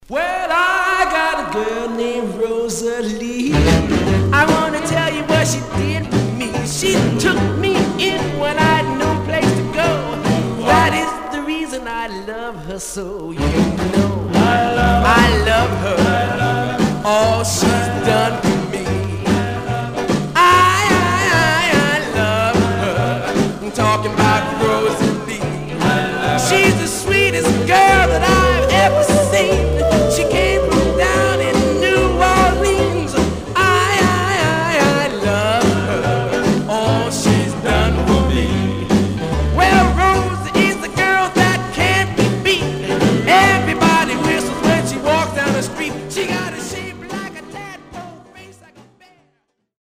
Condition Surface noise/wear Stereo/mono Mono
Male Black Groups